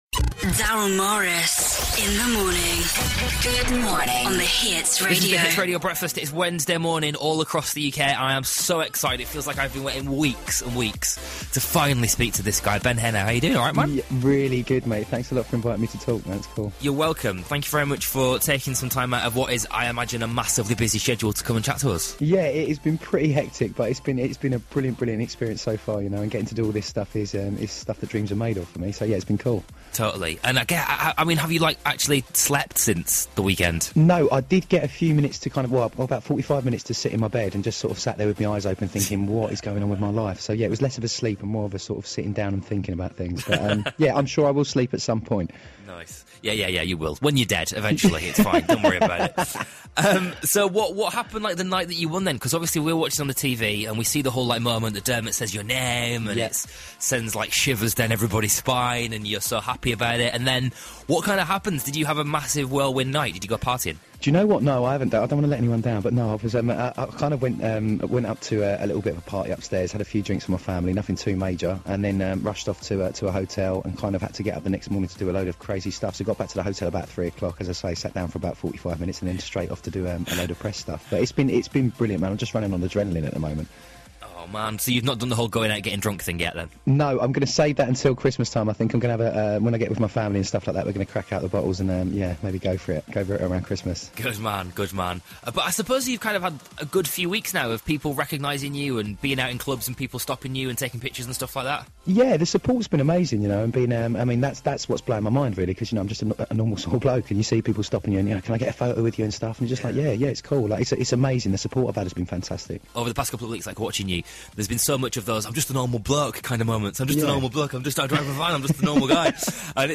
via satellite